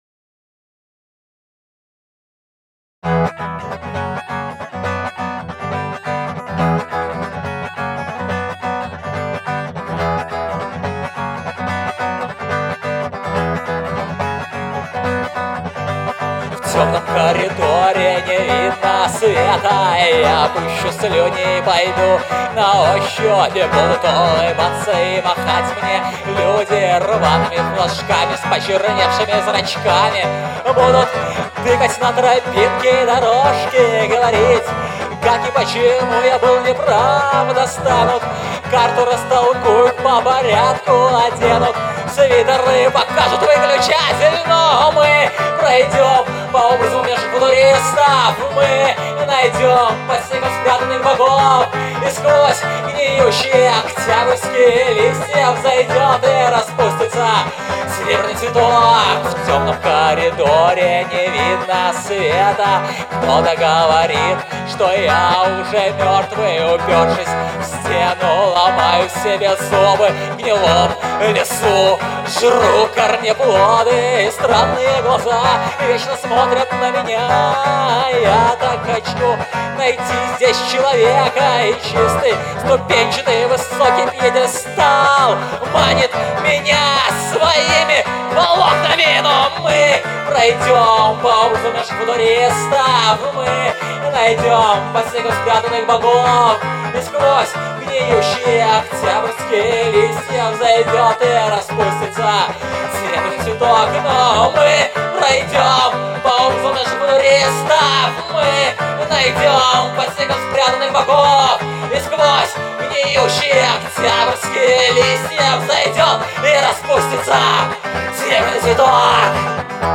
Музыкальный хостинг: /Альтернативная